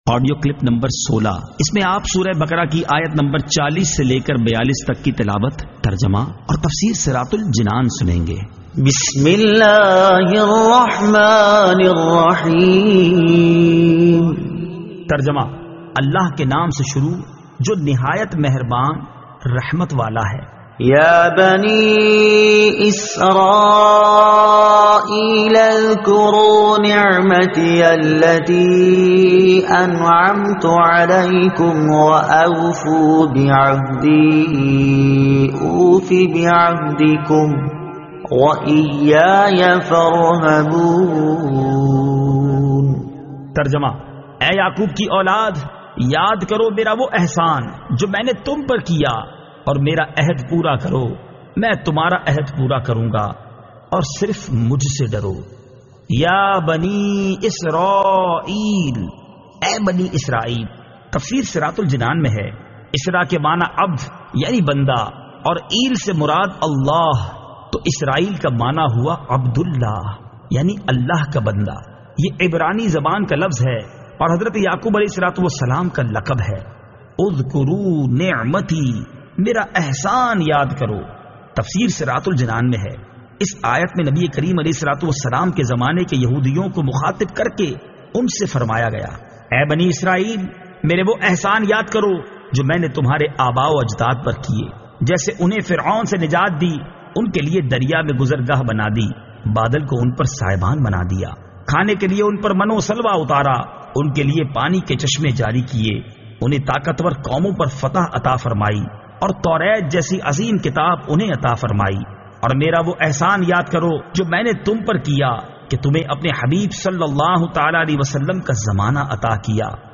Surah Al-Baqara Ayat 40 To 42 Tilawat , Tarjuma , Tafseer
2019 MP3 MP4 MP4 Share سُوَّرۃُ البَقَرَۃ آیت 40 تا 42 تلاوت ، ترجمہ ، تفسیر ۔